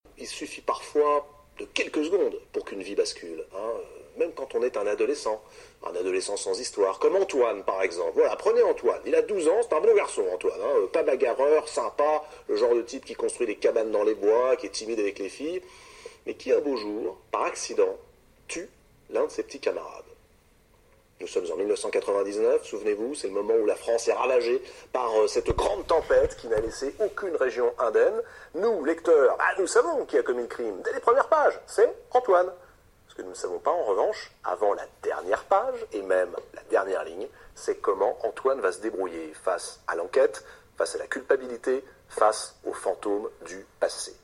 Voici comment en parlait Pierre Lemaître à la télévision il y a quelque temps.